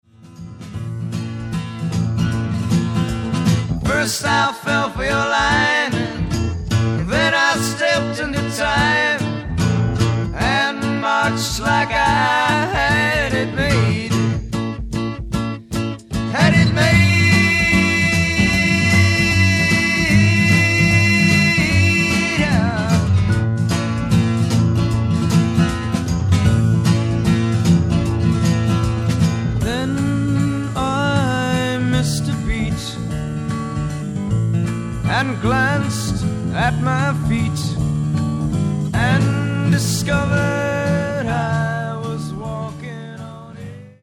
ジャズ、カントリー、ソウルなどを絶妙にブレンドして作られたサウンドはヒップかつクール、とにかく洗練されている。